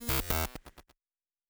pgs/Assets/Audio/Sci-Fi Sounds/Electric/Glitch 1_04.wav at 7452e70b8c5ad2f7daae623e1a952eb18c9caab4
Glitch 1_04.wav